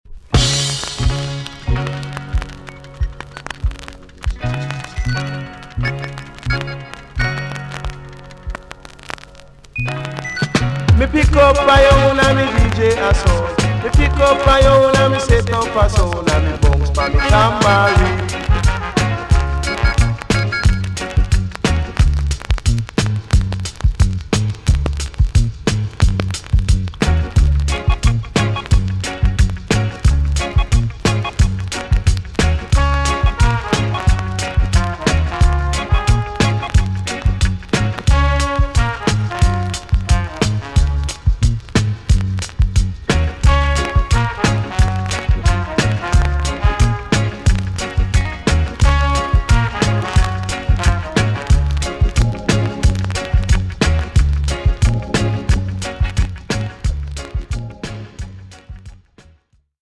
TOP >80'S 90'S DANCEHALL
VG+ 少し軽いチリノイズが入ります。
NICE TOASTING STYLE!!